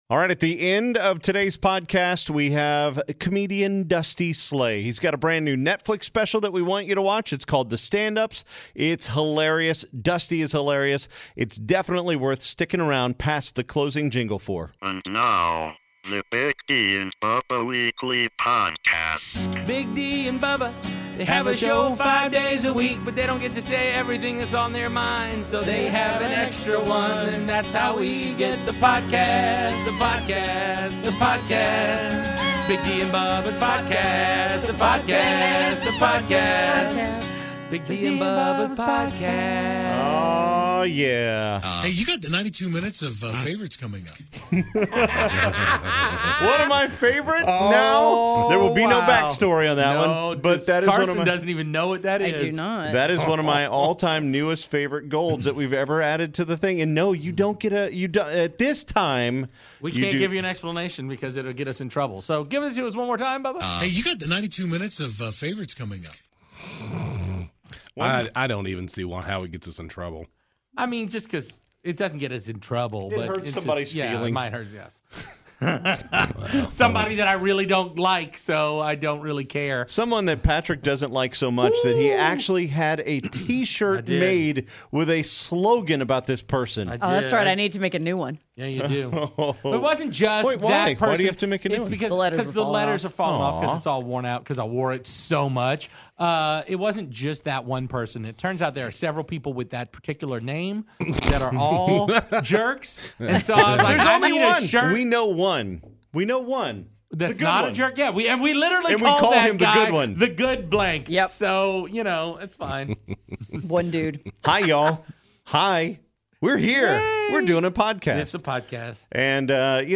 Comedian Dusty Slay joins us for Big D & Bubba's Weekly Podcast #369.